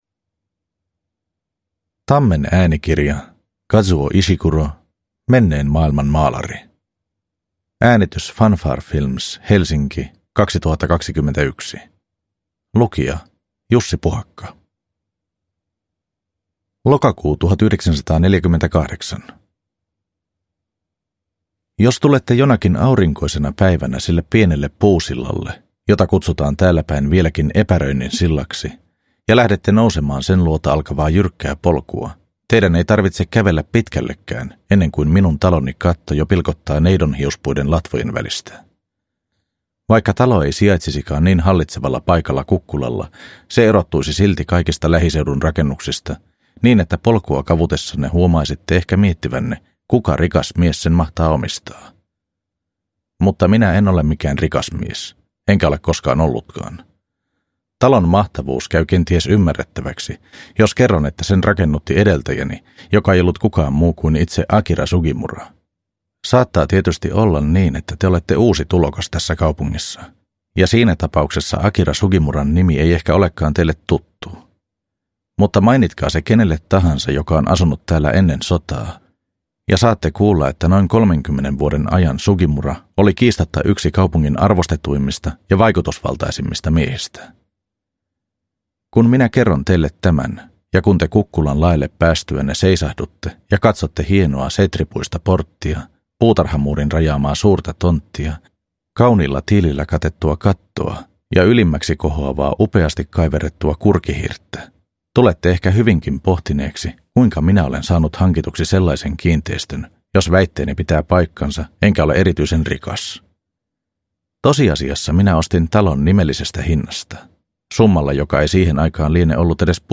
Menneen maailman maalari – Ljudbok – Laddas ner